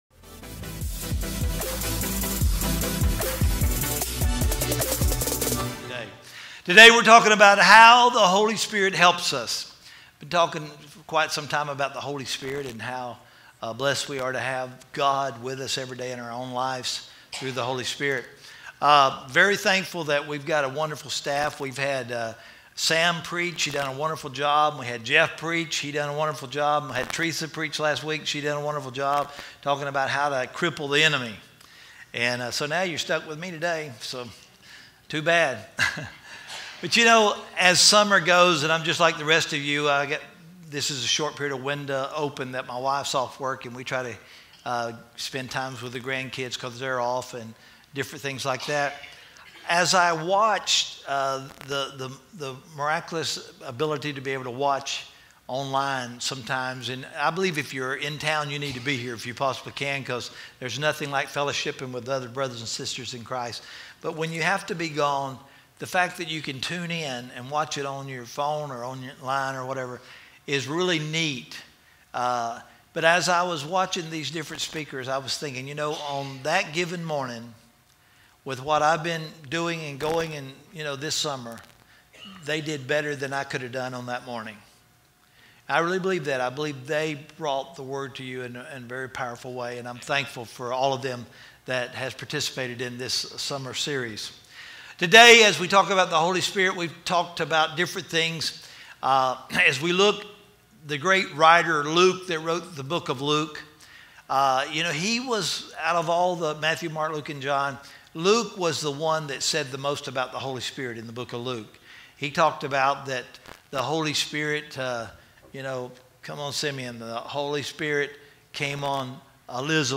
Listen to the conclusion of our sermon series “Holy Spirit”